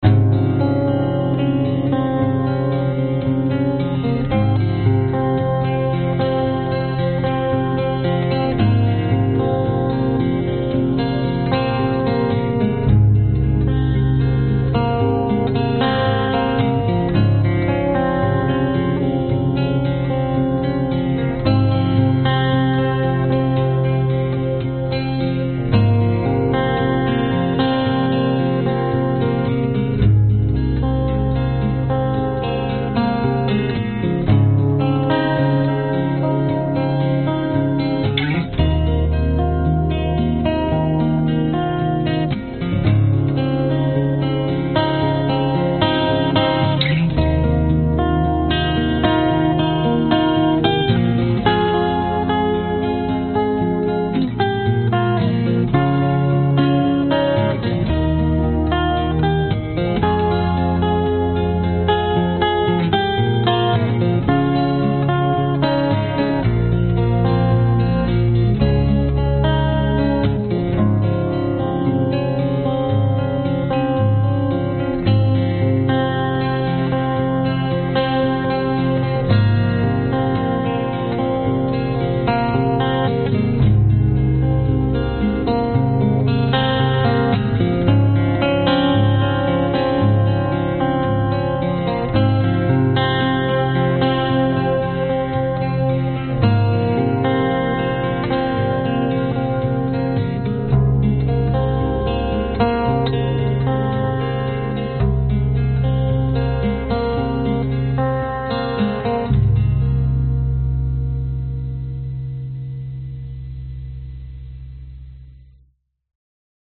描述：原声吉他主题，加入了合成器。
Tag: 吉他 原声 器乐 圆润 寒冷 忧郁 视频音乐 电影音乐 放松